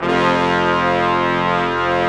Index of /90_sSampleCDs/Roland LCDP06 Brass Sections/BRS_Quintet/BRS_Quintet long